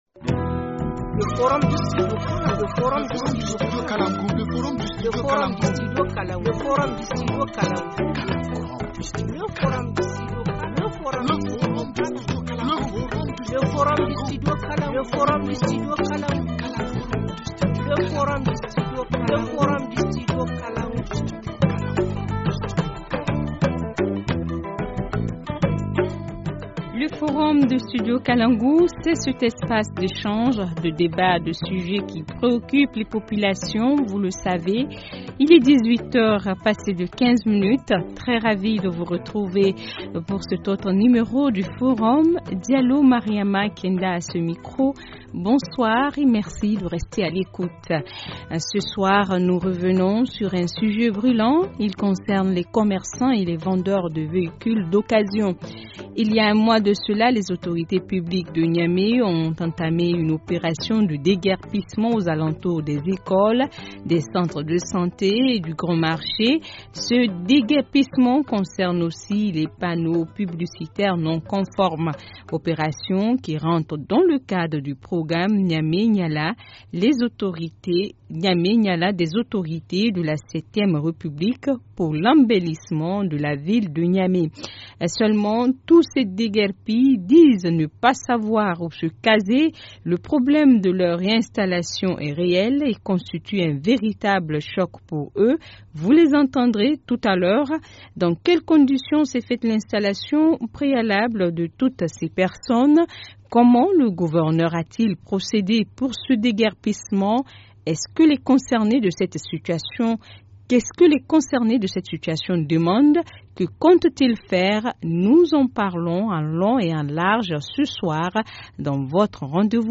Rediffusion du forum du 23 septembre 2016